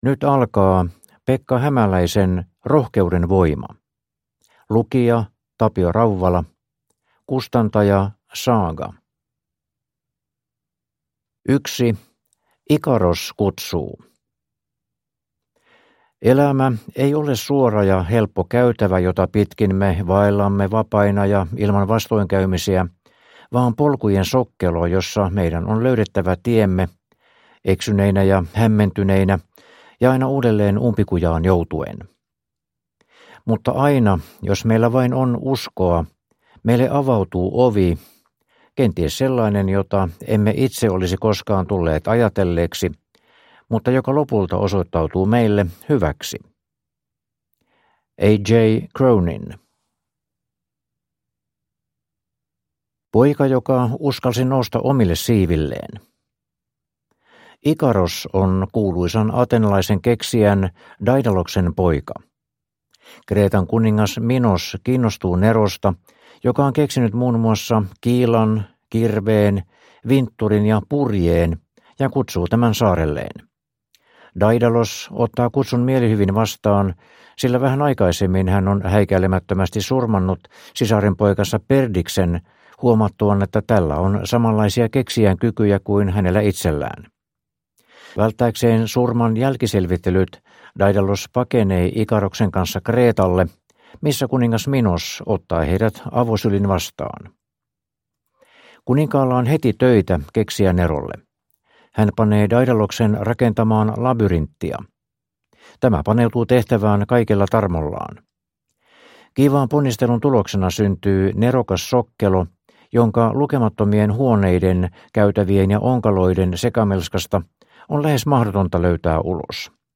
Rohkeuden voima (ljudbok) av Pekka Hämäläinen